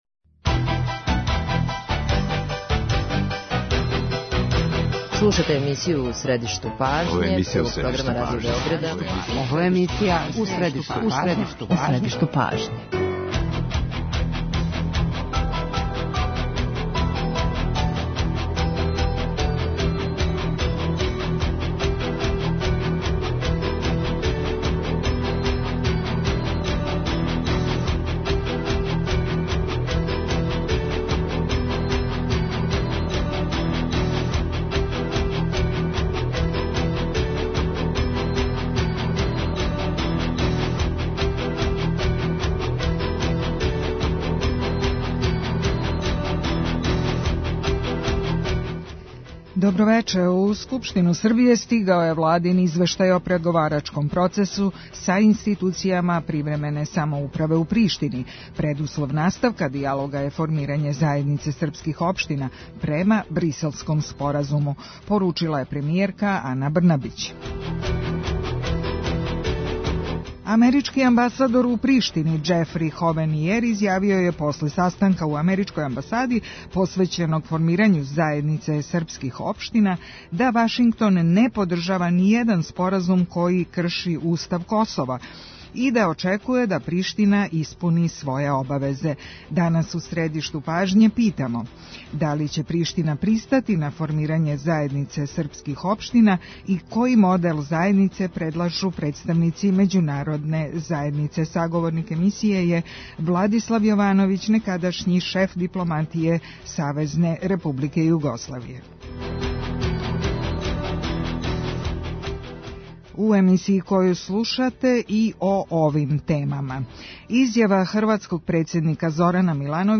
Саговорник емисије је Владислав Јовановић, некадашњи шеф дипломатије СР Југославије.